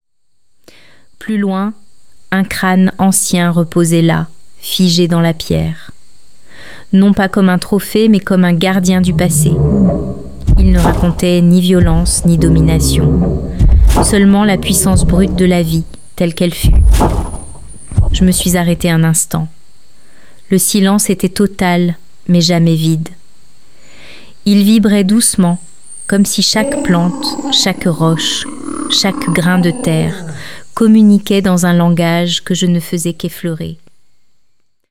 Jurassique_Legende_audio-feminin-exploration-Extrait.mp3